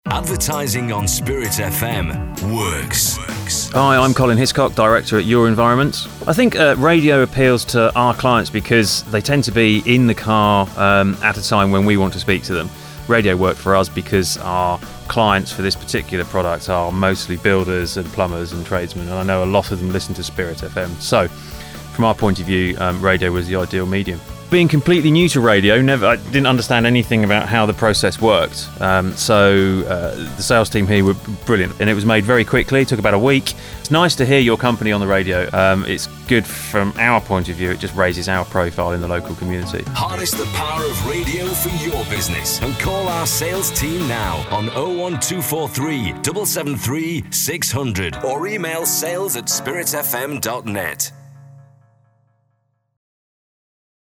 YourEnvironment - Testimonial for Spirit FM
We were asked to make a client testimonial for Spirit FM - our local radio station with whom we have advertised with for the past few months.